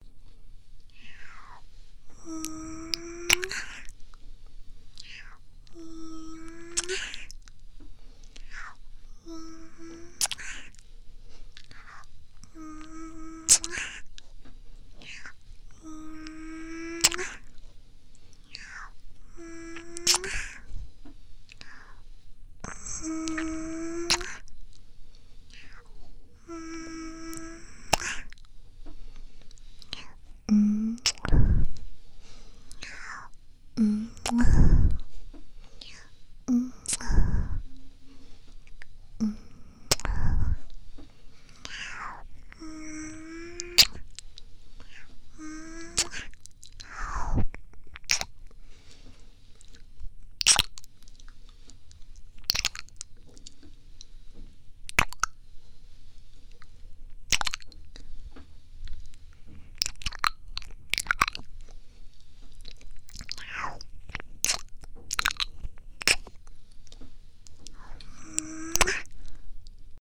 亲亲.mp3